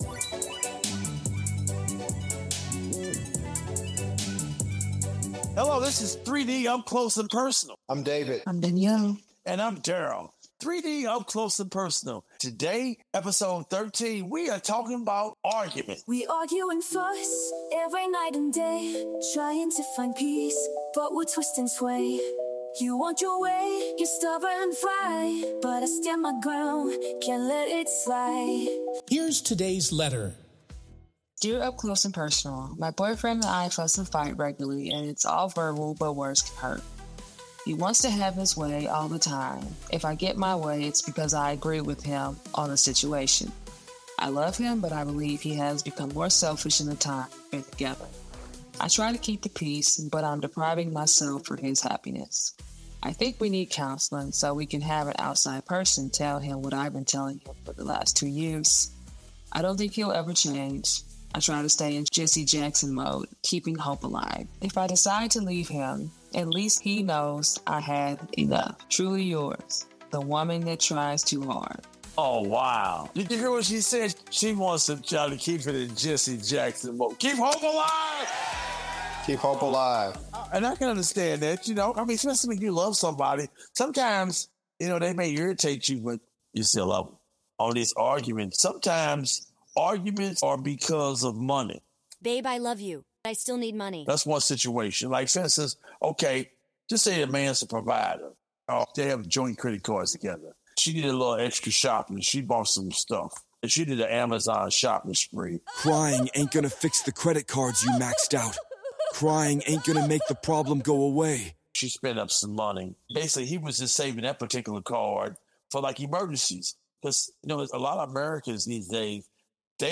The podcast features good production quality with engaging background music, humor, sound bites, and sound effects to keep you captivated and add vividness to every story.